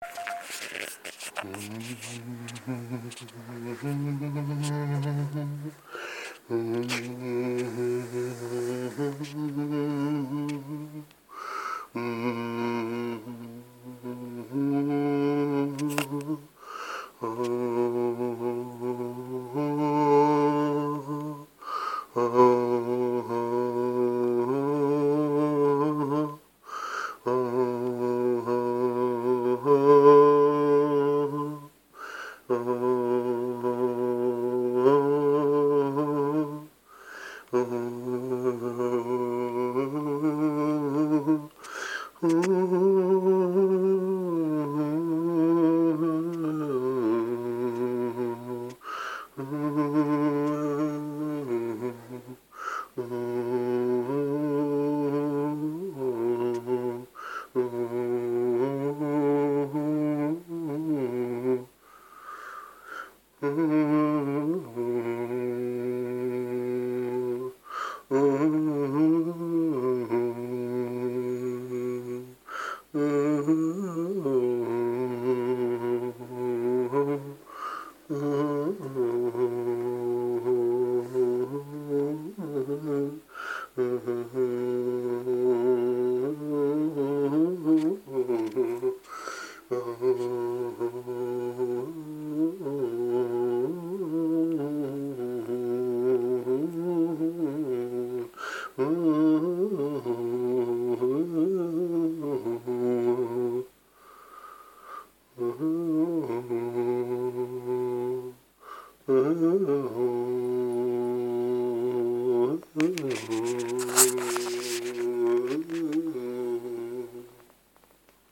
Sad vocal improv